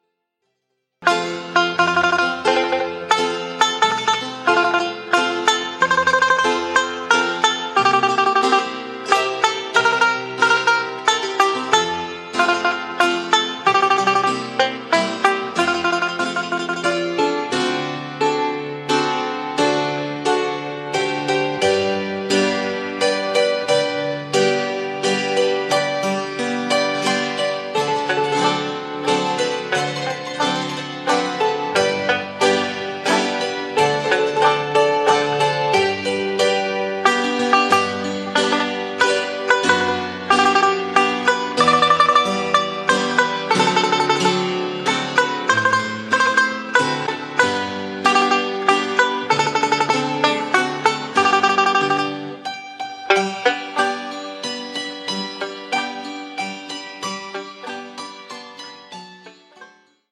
Traditional
6-beat intro.
This song is in 3/4 waltz time.